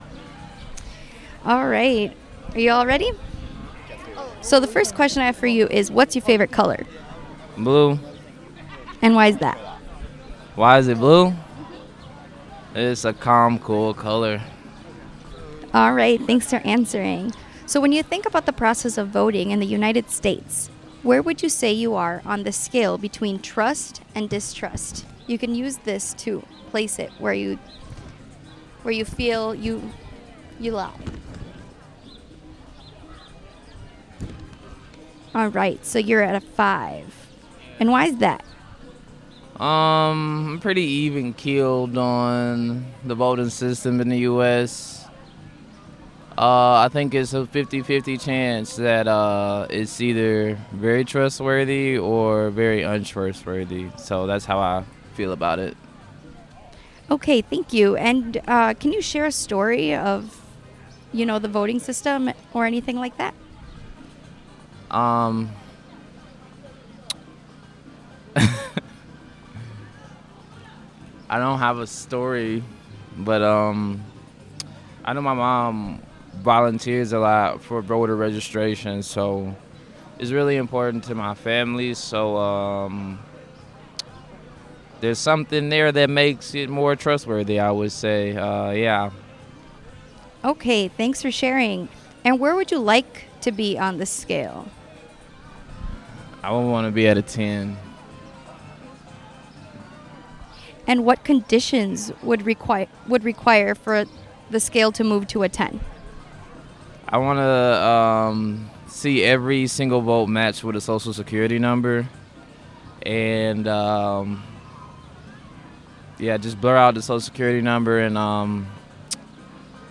Location Despensa de la Paz